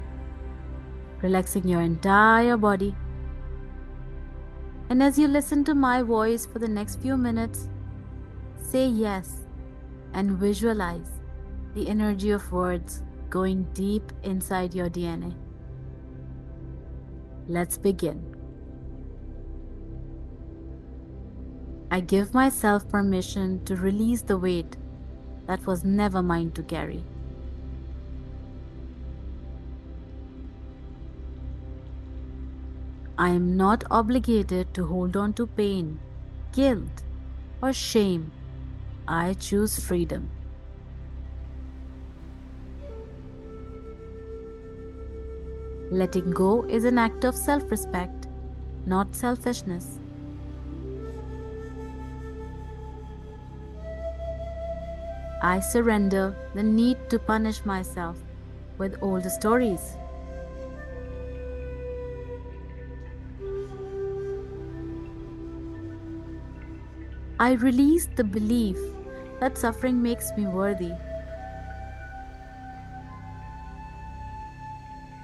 “Release & Rise” is a softly powerful guided meditation that helps you lay down what no longer belongs to you—not through effort, but through the quiet magic of:
– Sacred pauses- where the silence itself becomes healing
• Background music playing
• There are no breathing exercises or body scans in this meditation just soft guided breaths with pause.
• The background music in this meditation intentionally softens at certain points to support deeper introspection and calm.